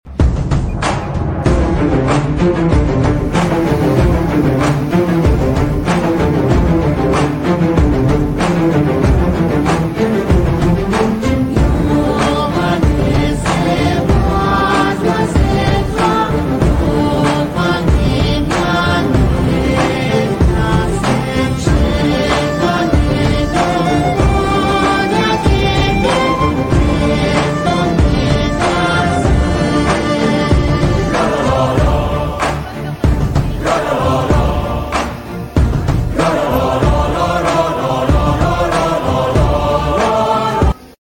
Dji Mini 3 Pro connect sound effects free download